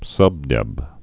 (sŭbdĕb)